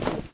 melee_holster_temp1.wav